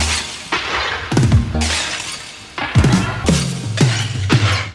Petit montage pris à trois moments différents de la chanson.